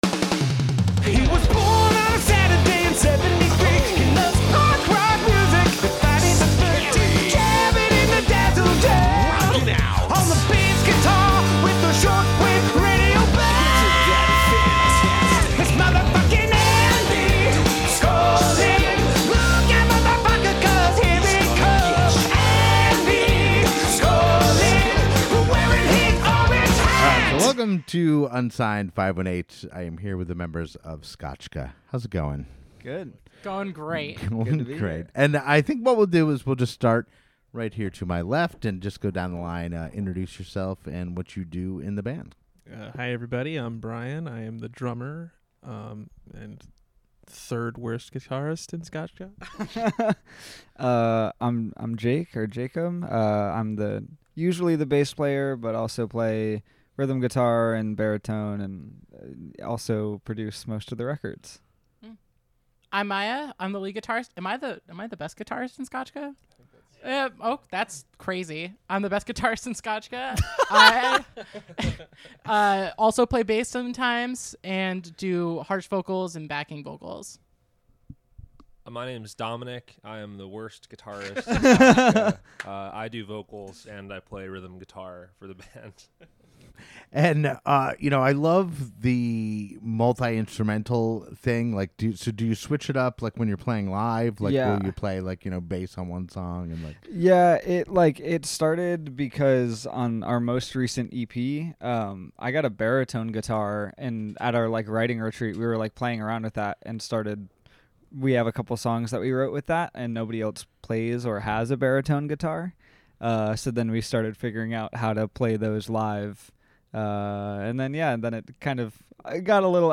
The members of Scotchka recently came by the Dazzle Den. We discussed the bands history, their approach to songwriting, their varied influences and much more.